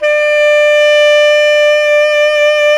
Index of /90_sSampleCDs/Roland L-CDX-03 Disk 1/SAX_Tenor V-sw/SAX_Tenor _ 2way
SAX TENORB0R.wav